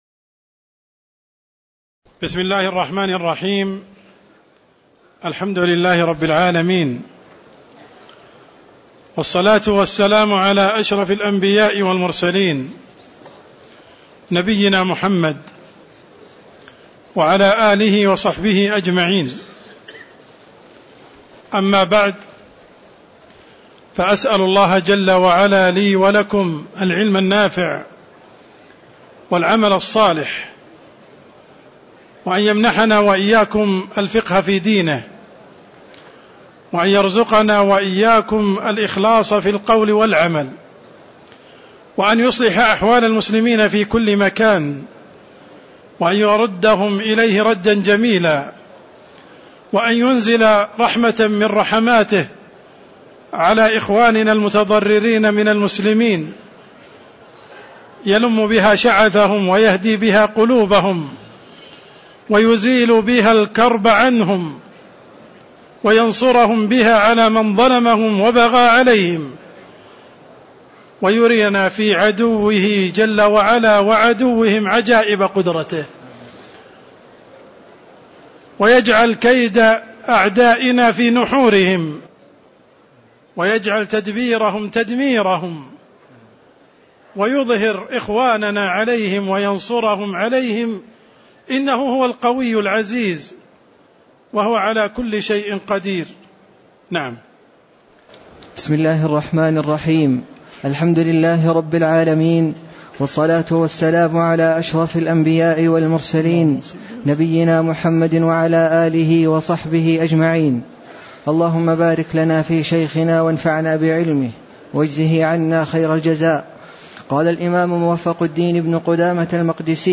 تاريخ النشر ٢٦ رجب ١٤٣٧ هـ المكان: المسجد النبوي الشيخ: عبدالرحمن السند عبدالرحمن السند باب صلاة الجمعة (18)